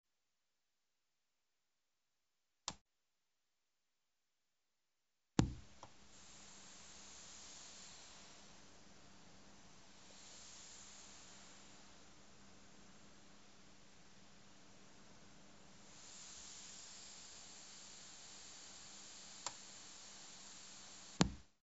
Steinberg UR28M Interface rauscht stark über das Monitoring
Ich nehme mein Smartphone und starte eine (ziemlich gute!) Aufnahme-App und halte sie an einen der beiden Satelliten meines Monitorings.
Bei ca. 2,5 Sekunden der Druck auf den On-Taster des Steinberg UR28M. Bei ca. 5,0 Sekunden springt das Interface an. Jetzt hört man deutlich ein Rauschen > ich bewege das Smartphone über die Mitteltöner und dem Hochtöner: Über dem Hochtöner hört man ein deutliche mehr Rauschen. Bei ca. 19,5 Sekunden der wiederholte Druck auf den - jetzt! - Off-Taster des Interfaces. Bei ca. 21 Sekunden geht das Interface aus.